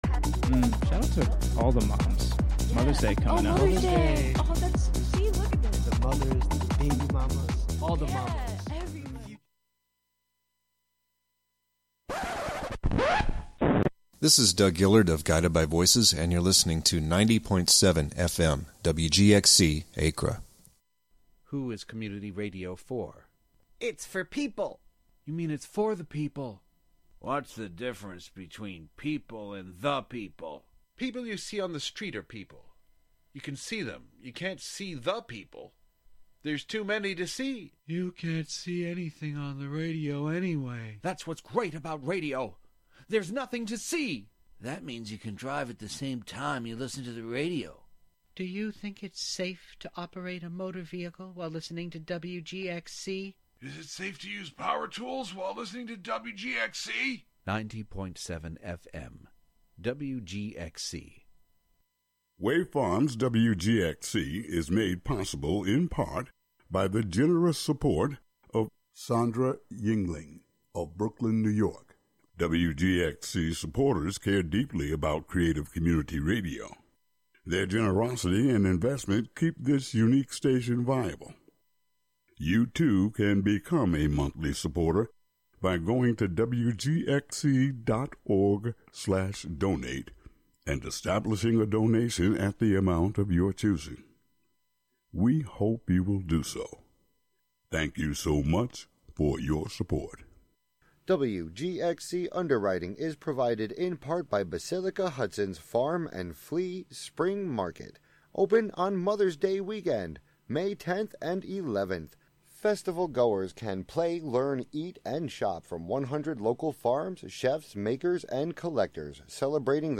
Broadcasting live from either The Spark of Hudson , the Hudson Thursday Market , or other locations out and about in the community.